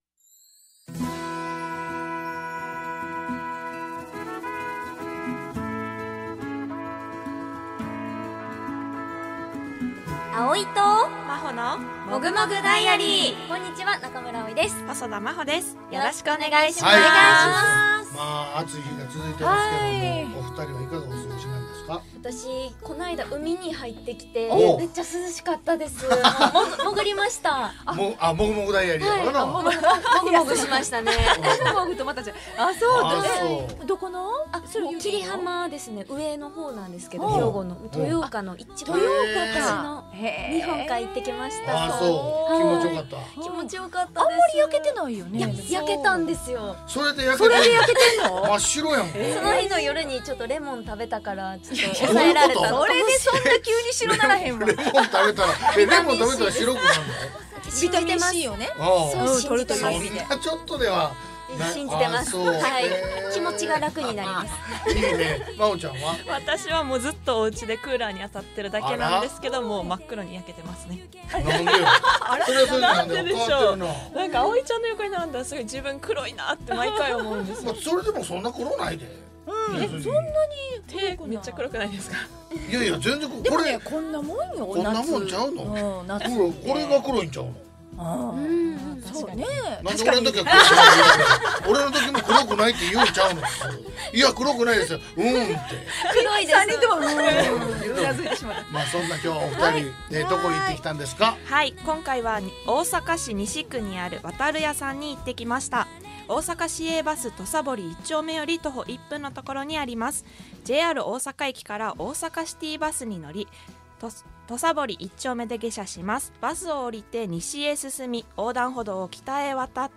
【放送音声】2023年8月7日放送回